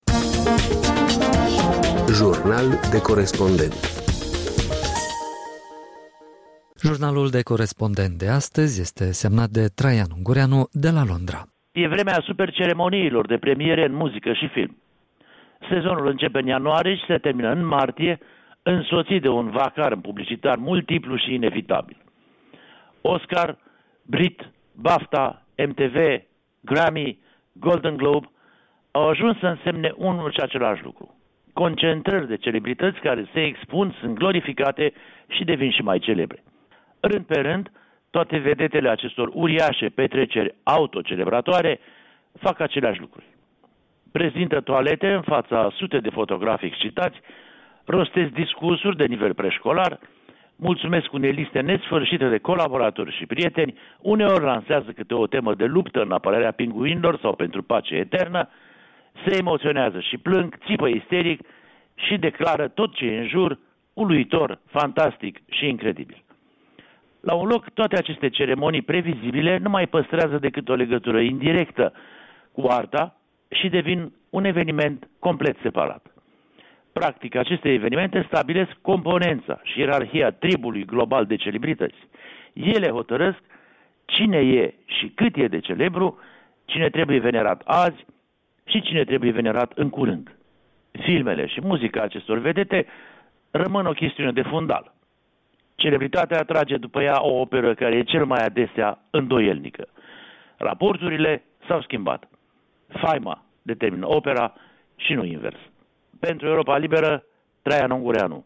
Jurnal de corespondent: Traian Ungureanu (Londra)